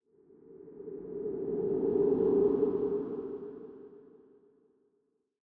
sfx_鬼影浮现.wav